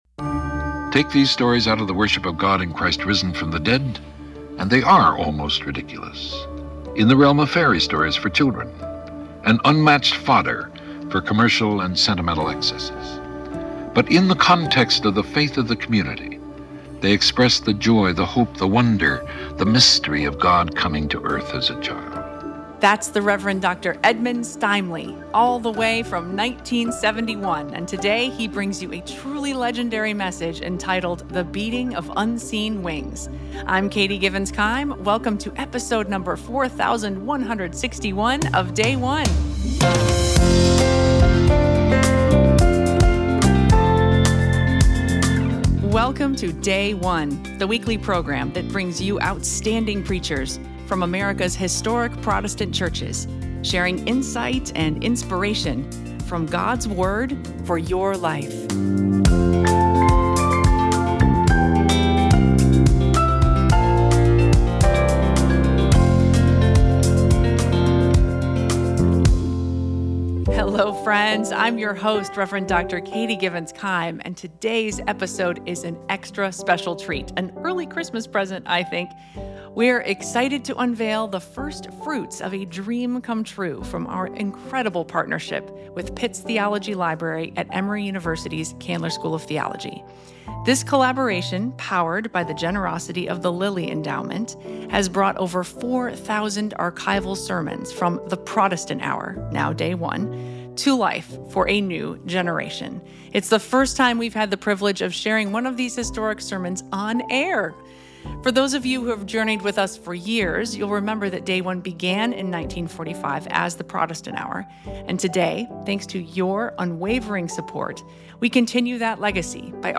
Evangelical Lutheran Church in America 4th Sunday of Advent - Year C Luke 1:39-45